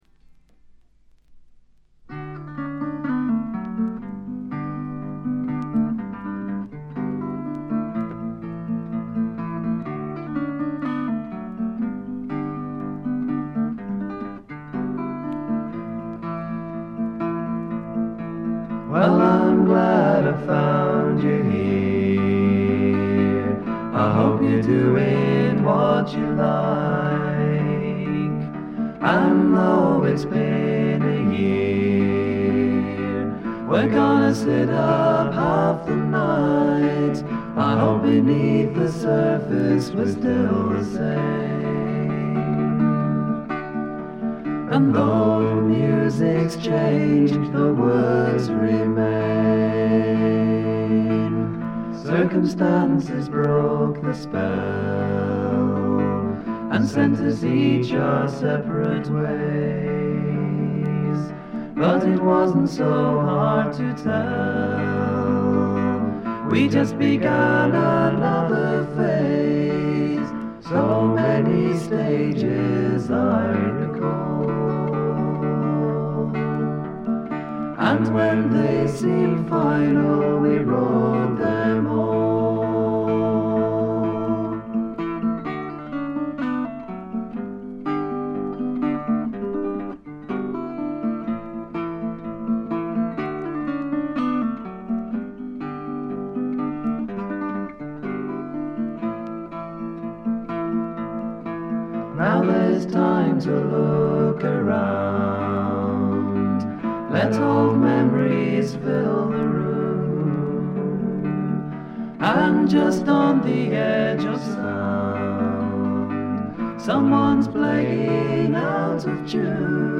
わずかなノイズ感のみ。
生きのよいフォークロックが最高ですよ。
試聴曲は現品からの取り込み音源です。